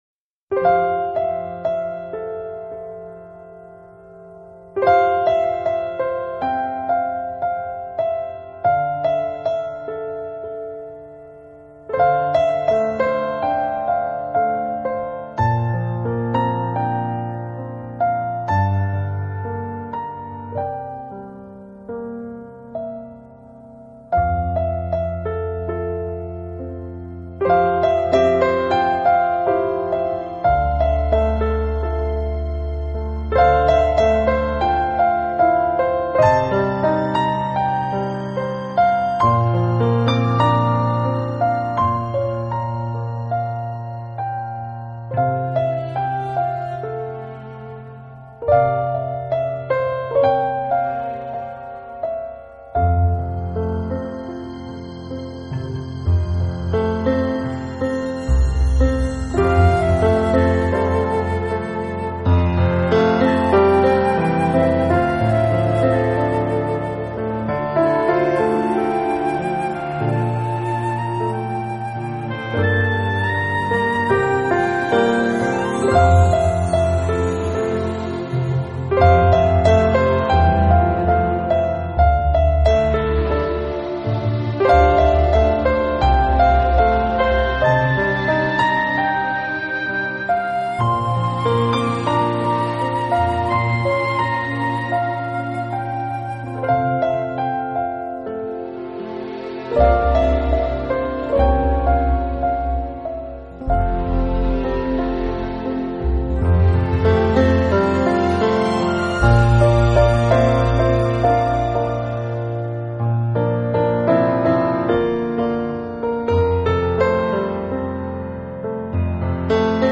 【纯色钢琴】